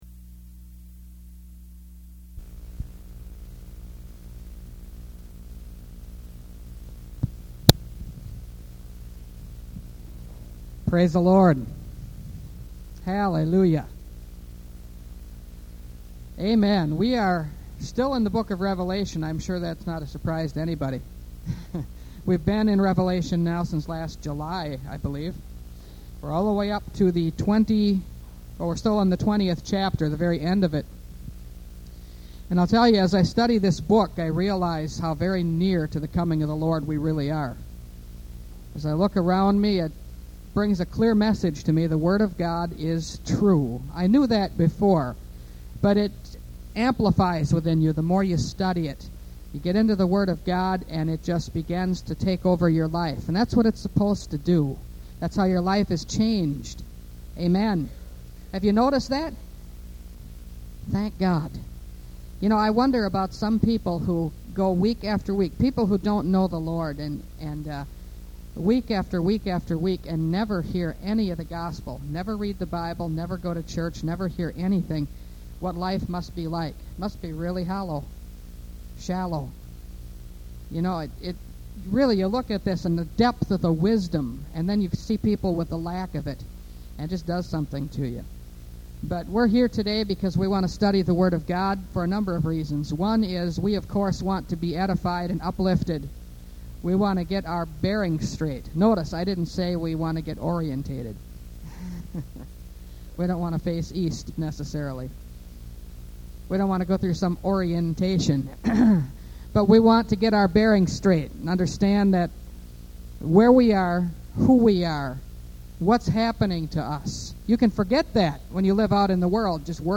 Revelation Series – Part 44 – Last Trumpet Ministries – Truth Tabernacle – Sermon Library